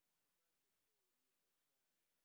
sp03_street_snr10.wav